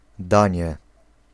Ääntäminen
US : IPA : [kɜː(ɹ)s] UK : IPA : /kɔːs/